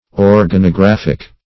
Meaning of organographic. organographic synonyms, pronunciation, spelling and more from Free Dictionary.
Search Result for " organographic" : The Collaborative International Dictionary of English v.0.48: Organographic \Or`ga*no*graph"ic\, Organographical \Or`ga*no*graph"ic*al\, a. [Cf. F. organographique.] Of or pertaining to organography.